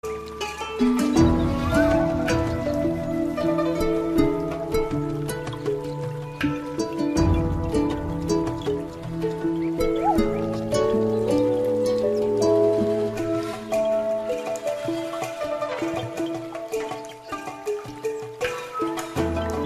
спокойные
релакс